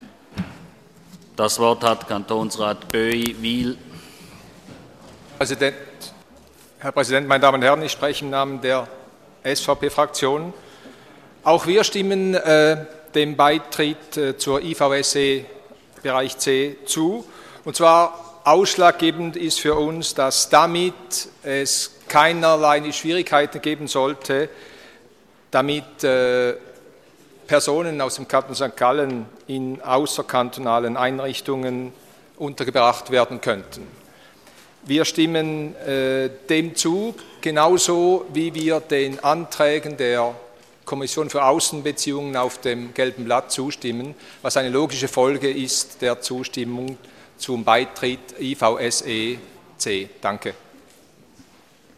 Session des Kantonsrates vom 24. und 25. Februar 2014